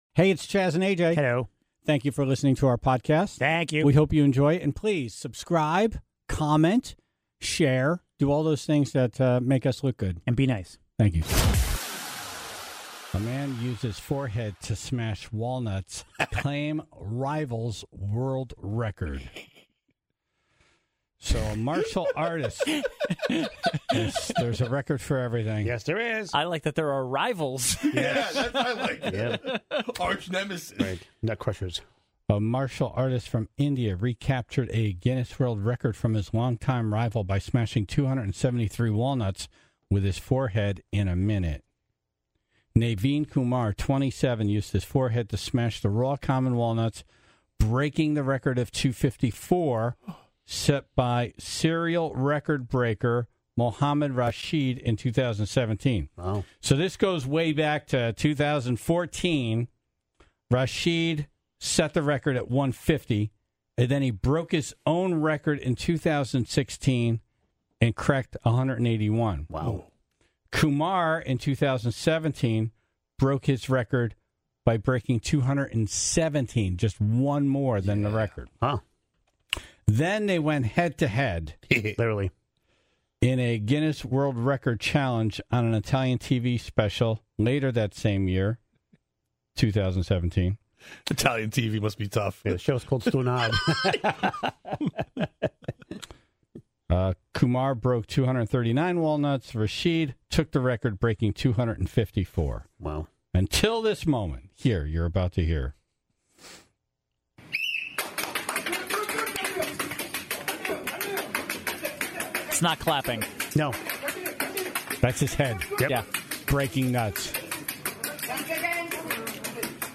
Dumb Ass News - A world record has been claimed (once again) by a man who smashed walnuts with his forehead. You can hear that he is clearly in pain throughout the one minute challenge.